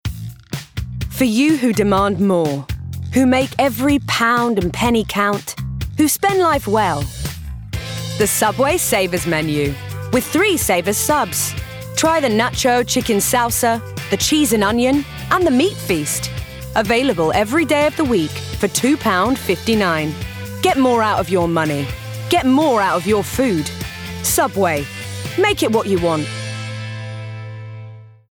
***NEW ARTIST*** | 20s-30s | Transatlantic, Genuine & Charismatic
Subway (RP)